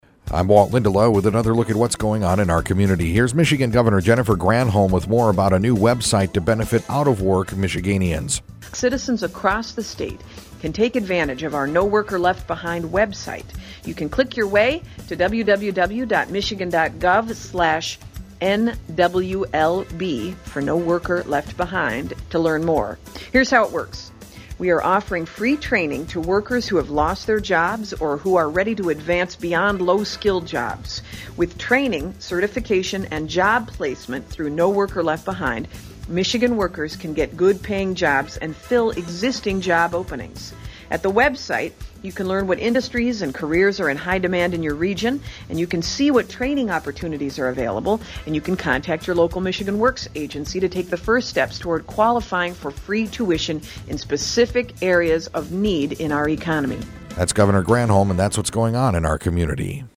Jennifer Granholm, Governor – New website to benefit jobless Michiganders